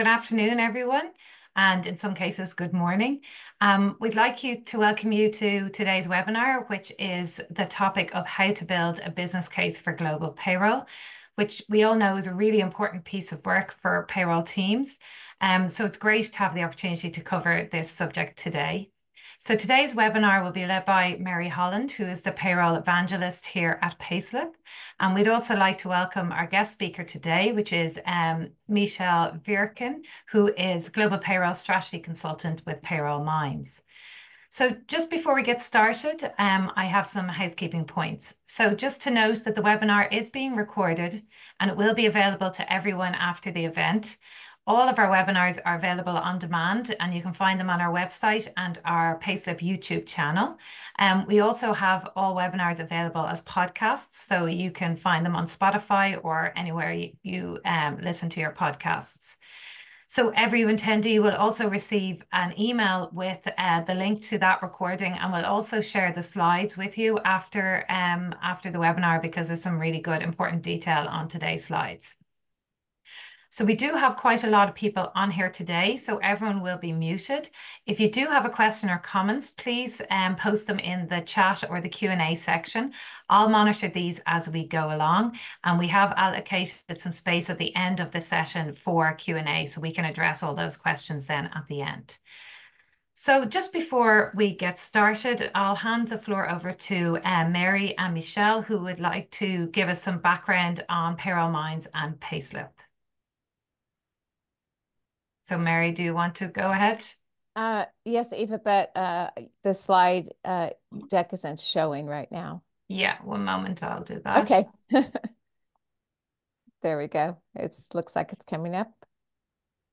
This webinar will help you prepare your business case for change.